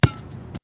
1 channel
fbkick04.mp3